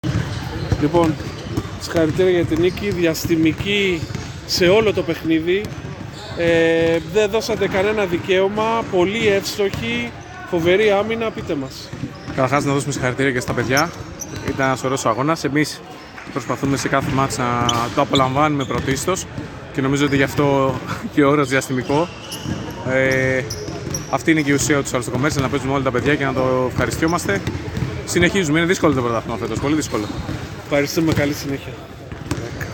GAMES INTERVIEWS
Παίκτης Novibet